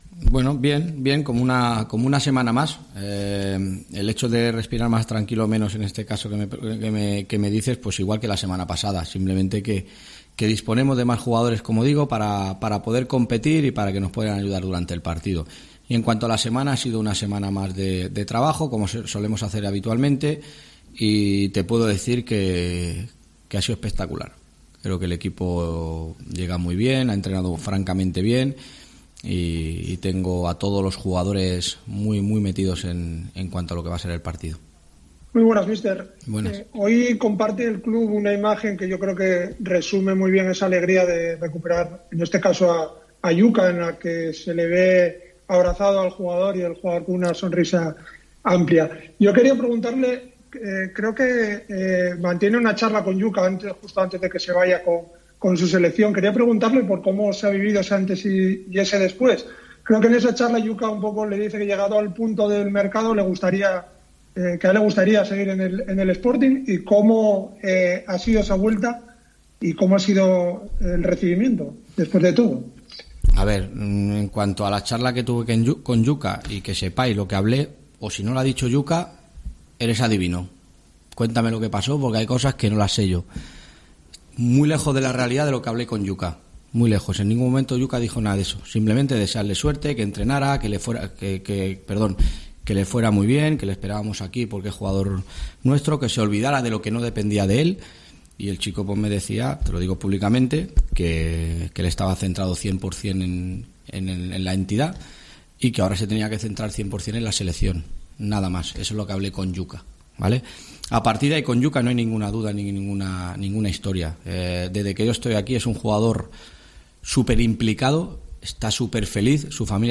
Rueda de prensa